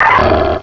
pokeemerald / sound / direct_sound_samples / cries / lucario.aif